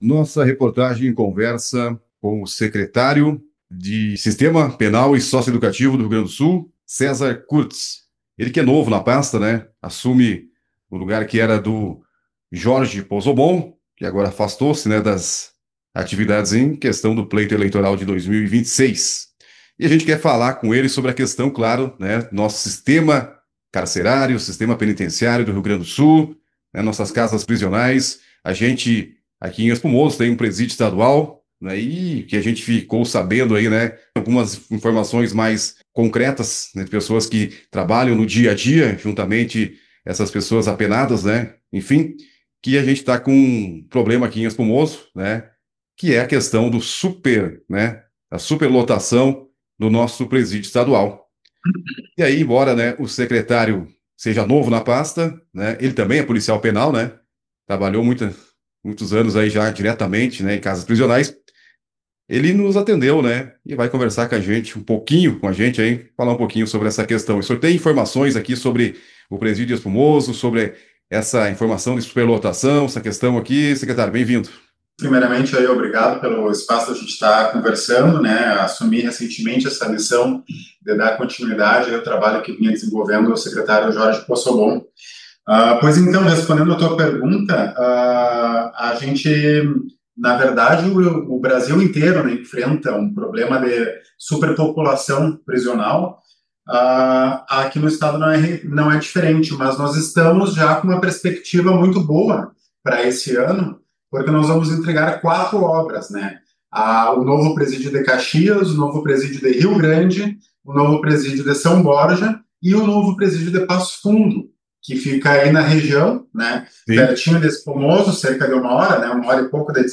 Nossa reportagem conversou com o secretário do Sistema Penal e Socioeducativo do Rio Grande do Sul, Cesar Kurz, que assumiu recentemente a pasta após o afastamento do então secretário Jorge Pozzobom, em função do pleito eleitoral de 2026.
Durante a entrevista, um dos principais temas abordados foi a situação do sistema prisional no Estado, especialmente a realidade do Presídio Estadual de Espumoso, que enfrenta atualmente um cenário de superlotação.